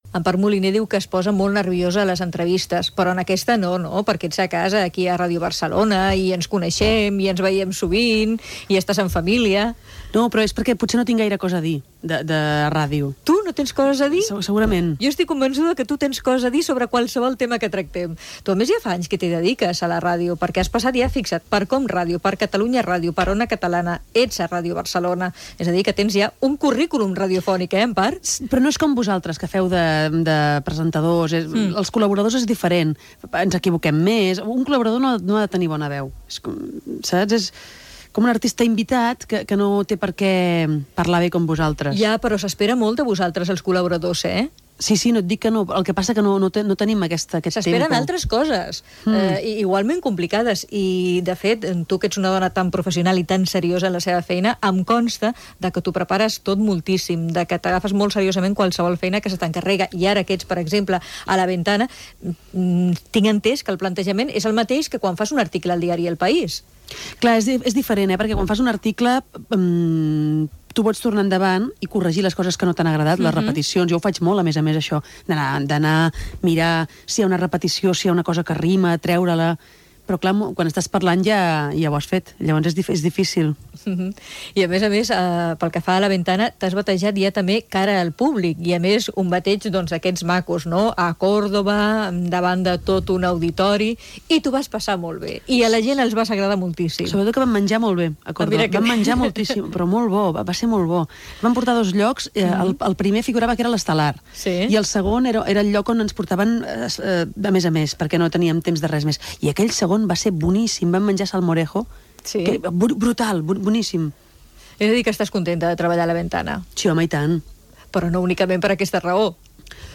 Entrevista a la periodista i escriptora Empar Moliner, que parla de les emissores on ha col·laborat
Divulgació